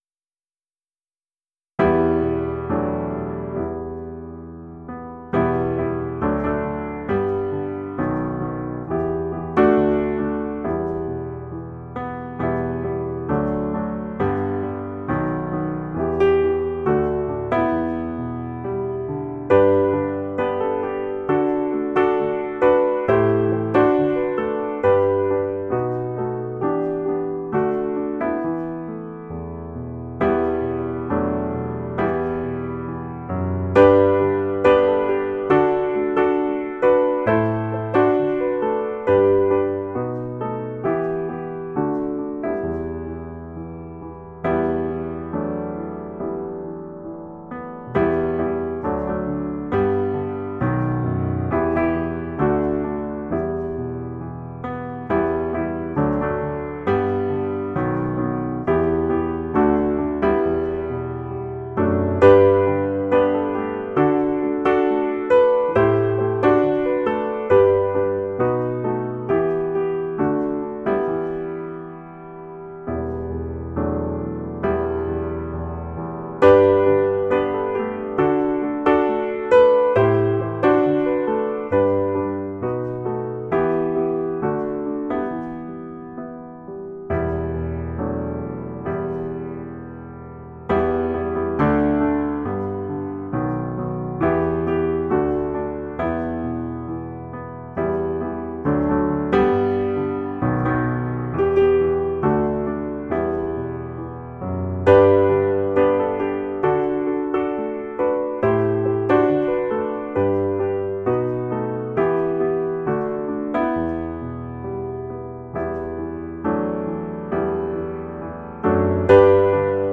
Ilouutinen -säestys